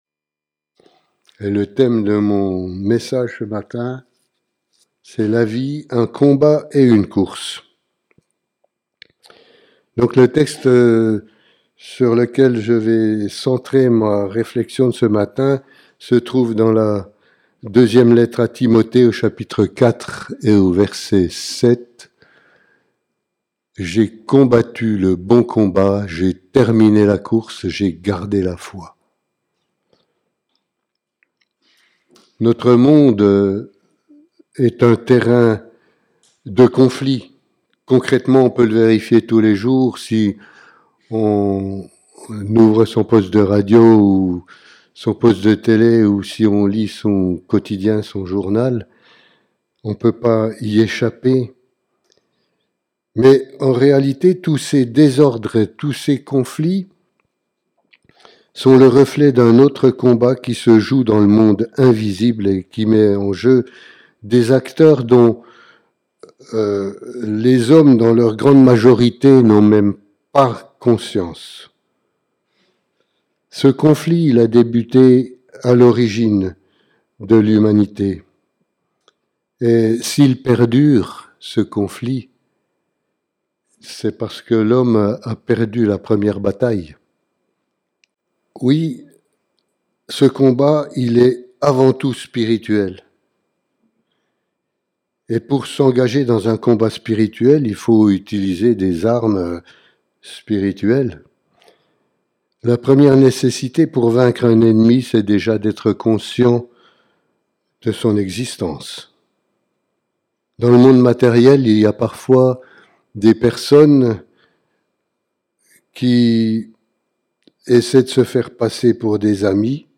Culte hebdomadaire - EEBS - Église Évangélique Baptiste de Seloncourt